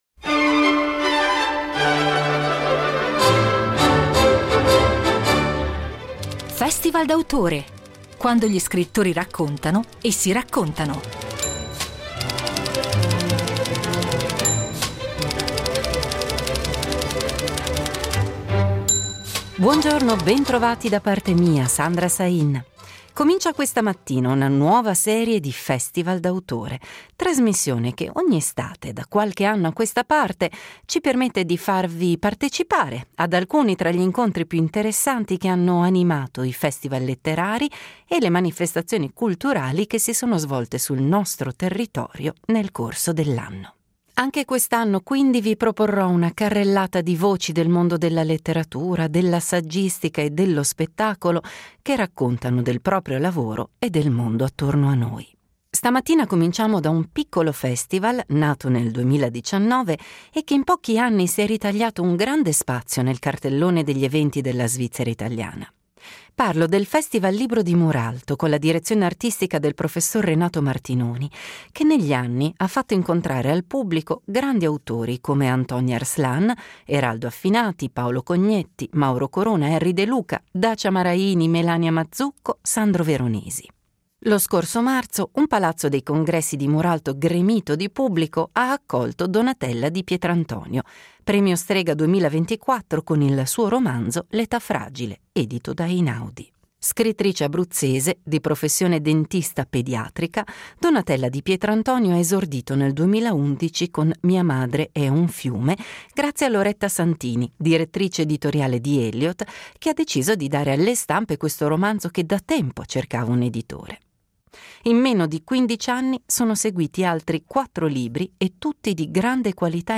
Incontro con Donatella Di Pietrantonio